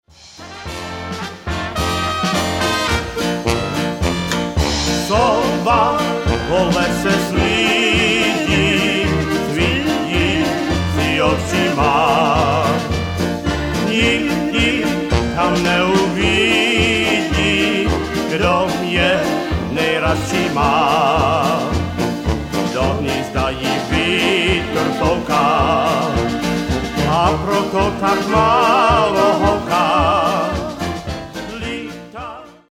Czech Music in Wisconsin
The onomatopoetic singing of the owl's hoot is appealing.
piano
trumpet
tuba
accordion